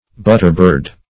Search Result for " butterbird" : The Collaborative International Dictionary of English v.0.48: Butterbird \But"ter*bird`\, n. (Zool.) The rice bunting or bobolink; -- so called in the island of Jamaica.